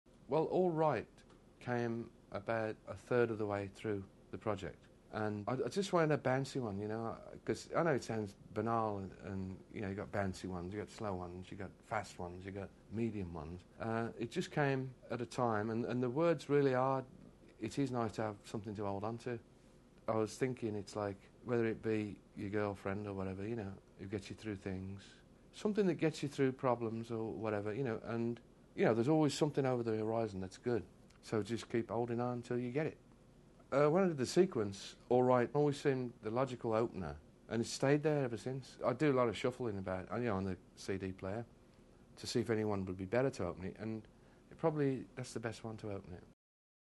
ELO Interview Disc